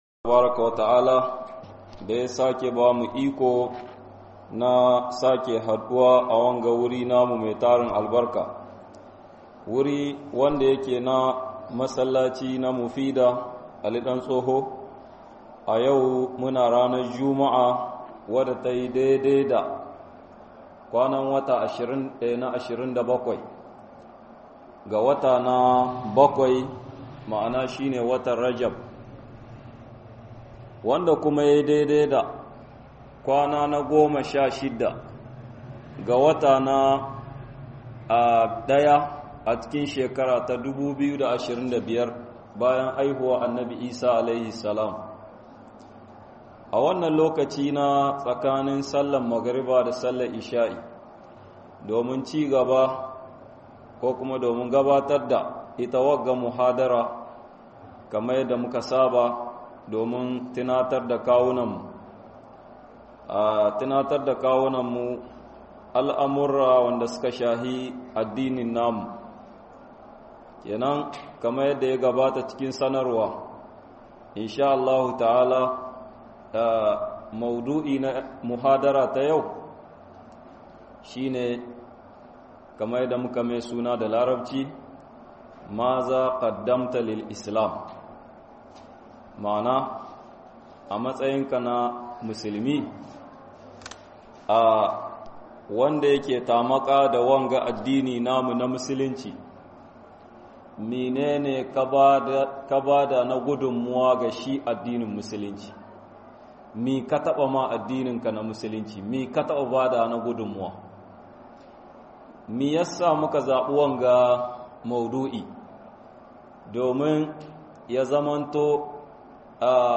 wata gudumuwa kaba addini - MUHADARA